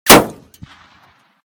metall03gr.ogg